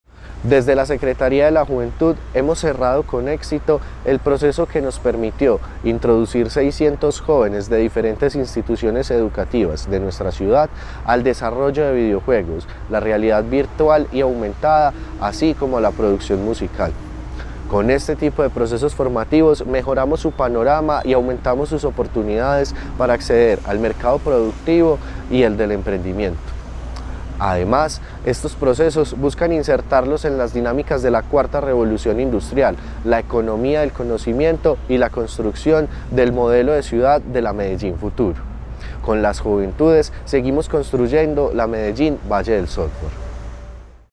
Palabras de Santiago Bedoya, secretario de la Juventud.